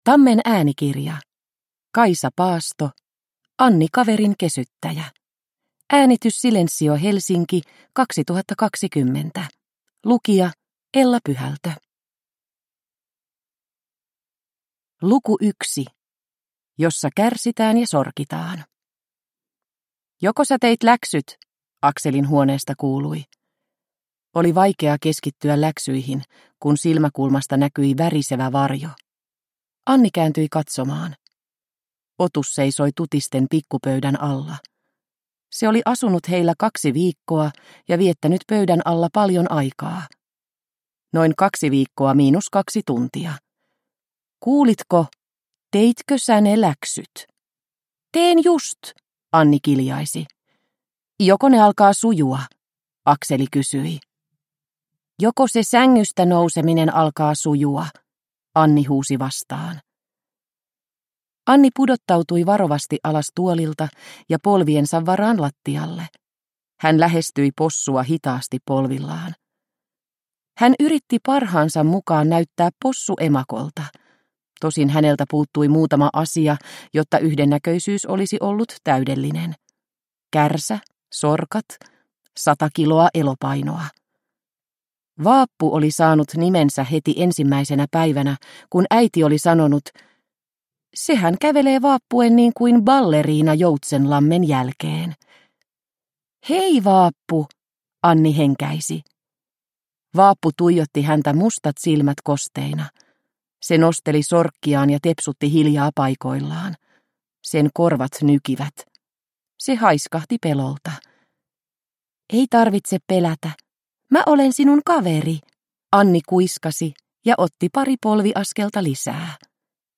Anni kaverinkesyttäjä – Ljudbok – Laddas ner